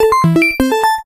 pai_boot.ogg